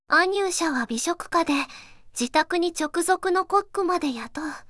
voicevox-voice-corpus
voicevox-voice-corpus / ROHAN-corpus /四国めたん_セクシー /ROHAN4600_0021.wav